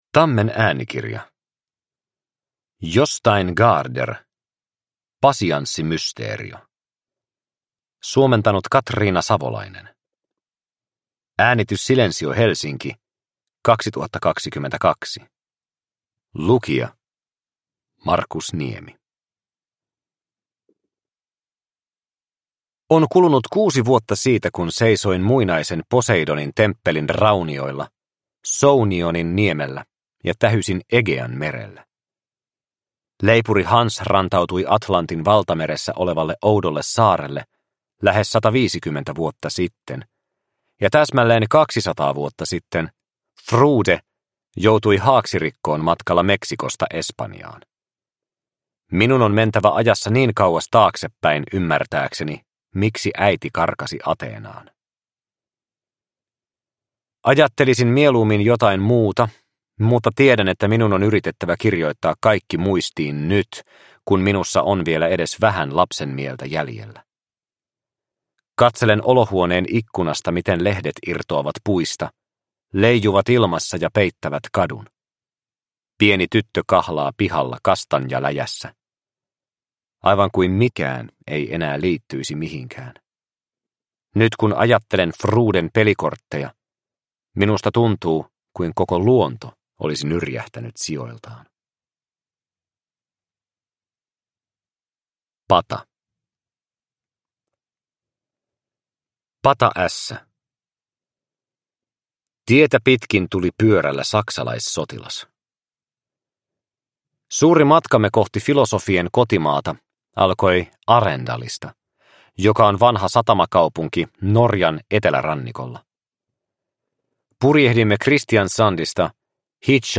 Pasianssimysteerio – Ljudbok – Laddas ner